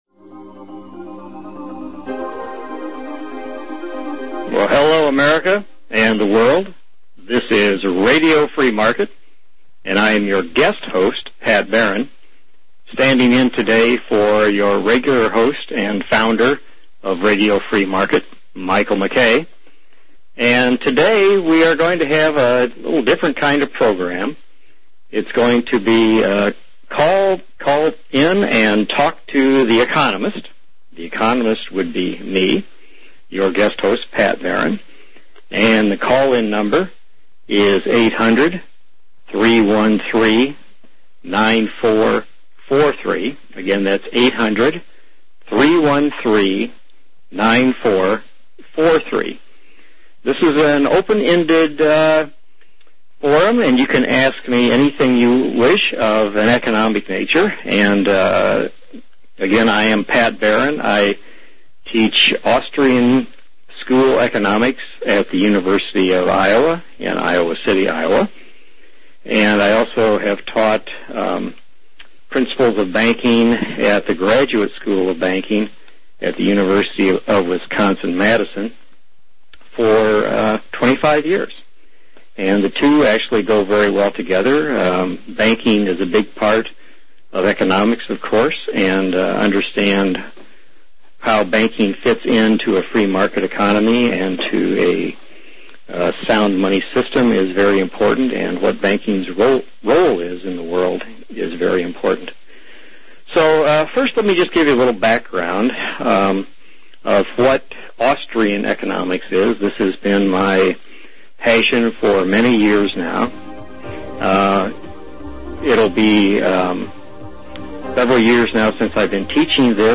**LIVE CALL-IN SHOW
Join us for this *interactive* show where YOU can call-in and discuss issues, ask questions and get answers – and perspective – regarding ‘What-The-Heck is Happening in the World.’